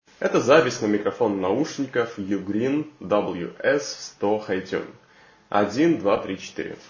🎙 Микрофон
В качестве гарнитуры наушники звучит на уровне других бюджетников TWS до 50$, то-есть плохо.
Производитель заявляет о технологии шумоподавления микрофона cVc 8.0 — по итогу это оказалось не более чем маркетинг, вы сами можете все слышать: